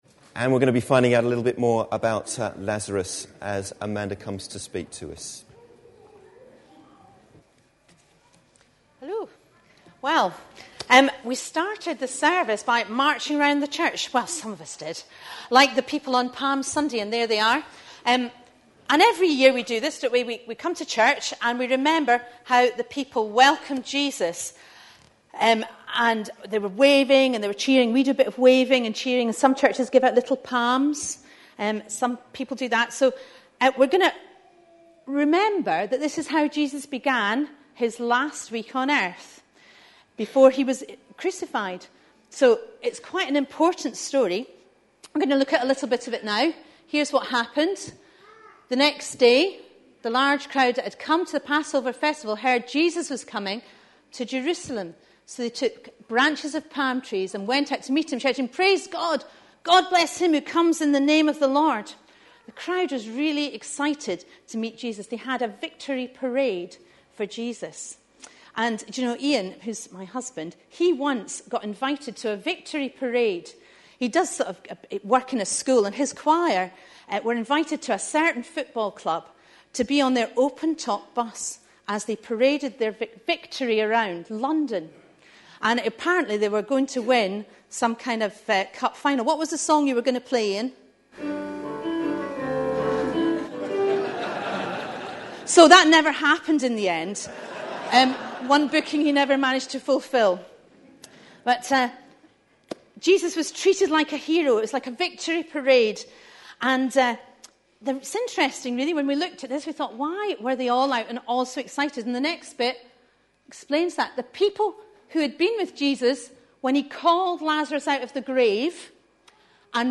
A sermon preached on 17th April, 2011, as part of our A Passion For.... series.
John 11 Listen online Details Readings are John 11:17-27 and 38-44. Various pictures were shown during the talk (with references to a video clip shown earlier), which was part of a family (all-age) service introducing the week leading up to Easter.